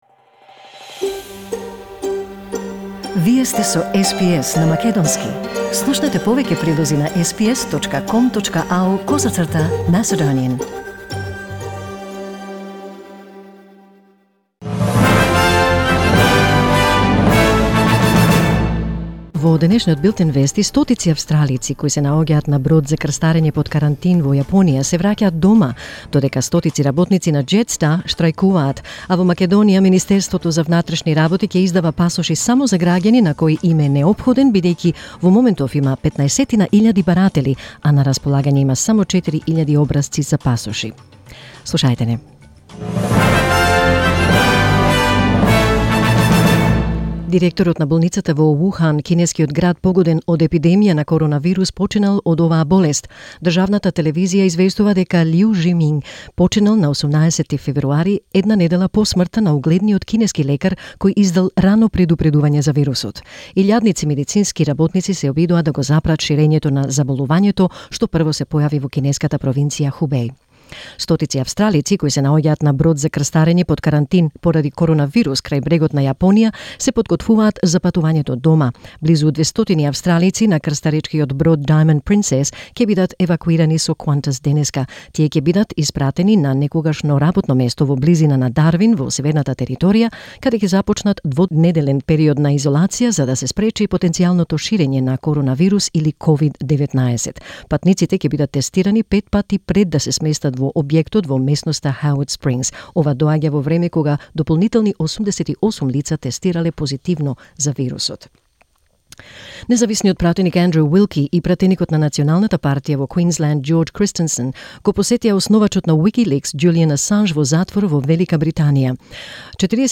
SBS News in Macedonian 19 February 2020